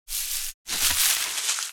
596コンビニ袋,ゴミ袋,スーパーの袋,袋,買い出しの音,ゴミ出しの音,袋を運ぶ音,
効果音